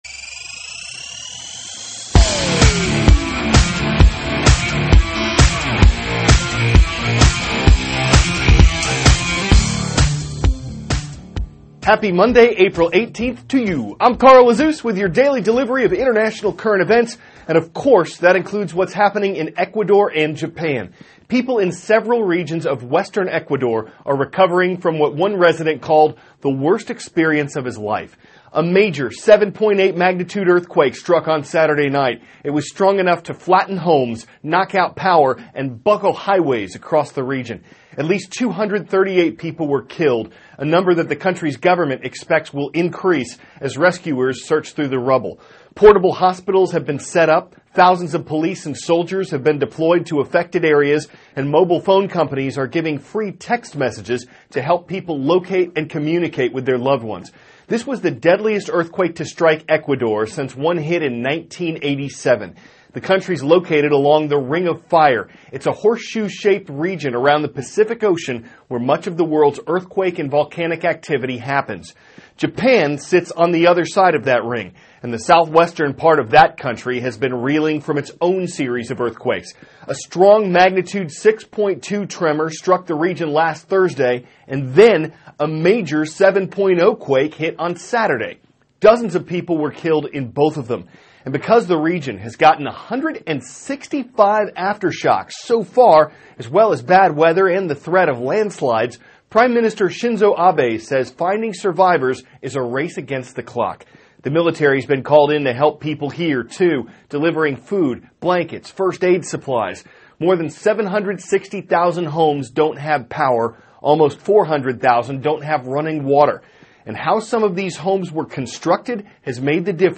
(cnn Student News) -- April 18, 2016 Earthquakes Claim Lives and Homes in Ecuador and Japan; Divers Excavate a Historic Shipwreck Near Oman; The Effects of Space on the Human Body. Aired 4-4:10a ET THIS IS A RUSH TRANSCRIPT.